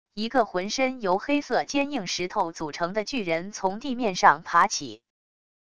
一个浑身由黑色坚硬石头组成的巨人从地面上爬起wav音频